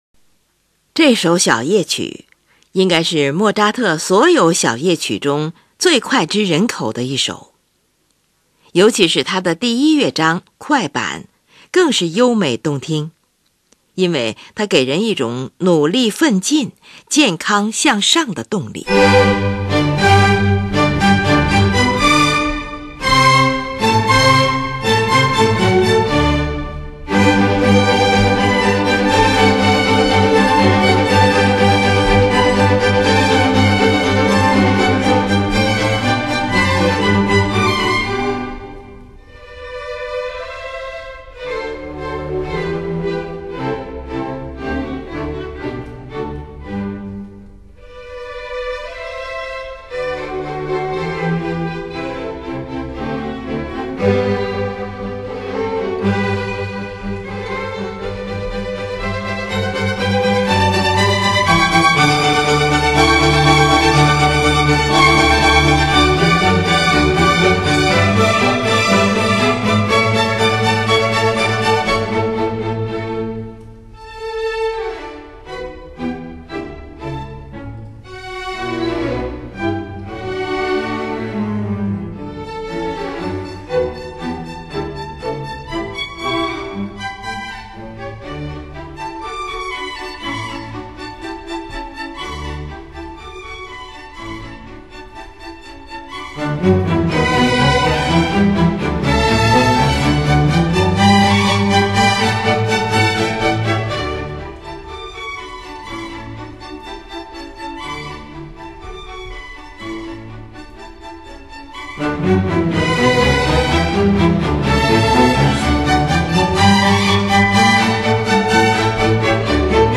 尤其是他的第一乐章“快板”，主题轻盈、典雅，简洁明快，曲调优美动听，同时给人一种努力奋进，健康向上的动力。